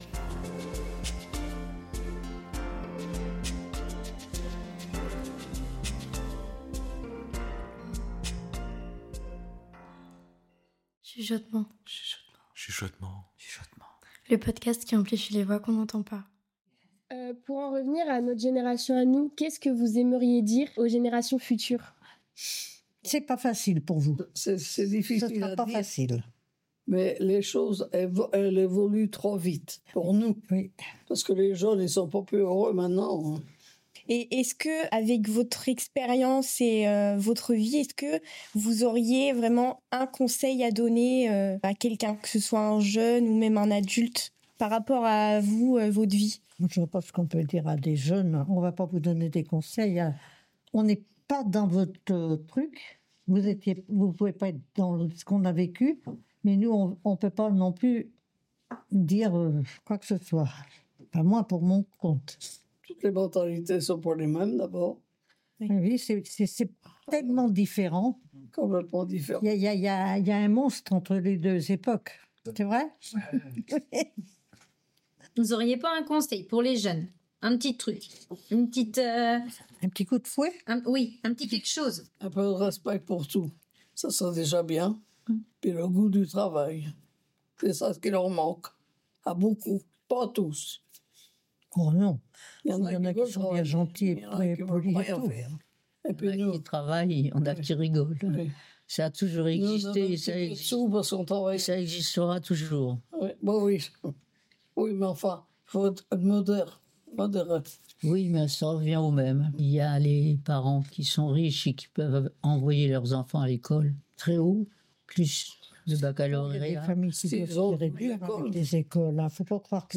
Cet épisode est le deuxième d'une série réalisée avec les résident·es de l'EHPAD Vivre ensemble à La Jumellière. En 3 étapes, nous plongeons dans leurs récits de jeunesse, leurs histoires émouvantes et les moments marquants de leur vie. Dans cet épisode, on parle de l'importance du travail, de beaux souvenirs de jeunesse et de rencontres au bal.